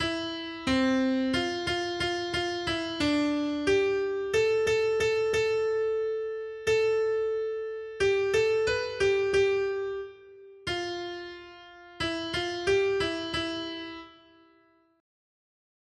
Noty Štítky, zpěvníky ol468.pdf responsoriální žalm Žaltář (Olejník) 468 Skrýt akordy R: Tvou dobrotu, Pane, mám na zřeteli. 1.